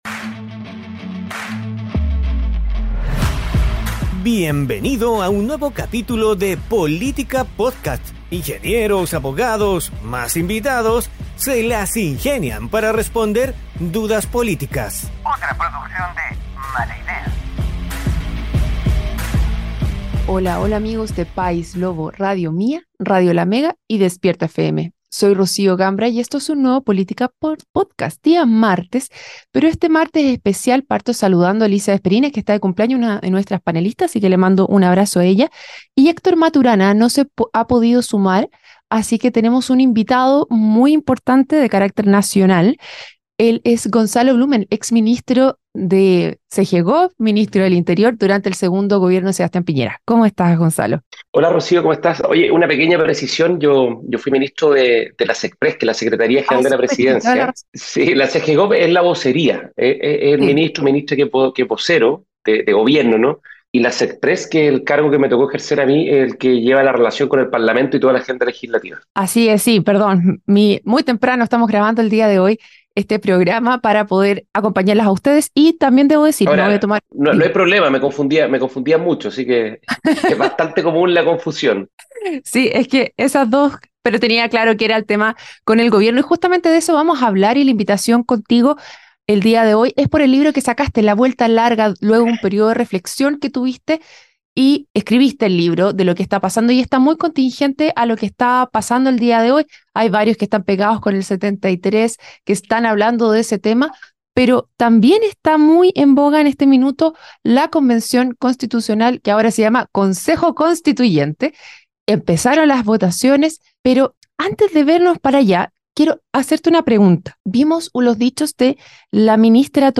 un panel de expertos estables e invitados especiales